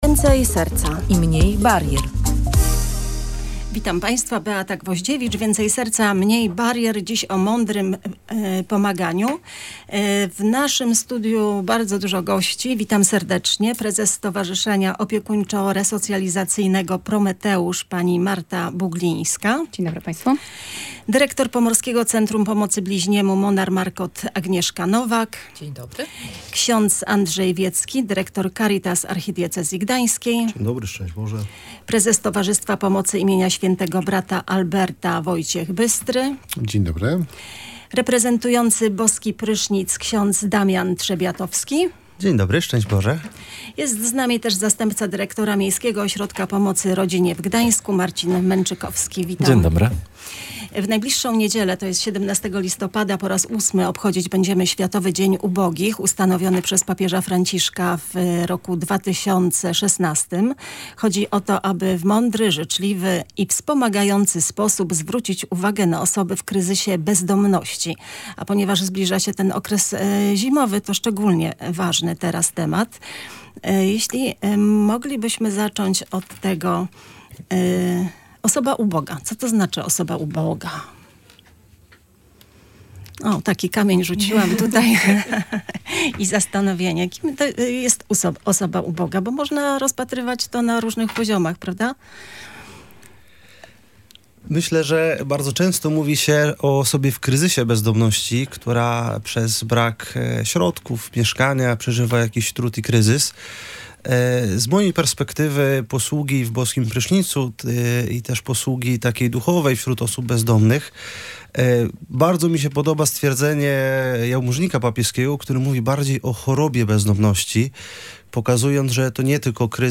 W naszym studiu gościliśmy przedstawicieli sześciu organizacji pomagających ubogim i wymagającym wsparcia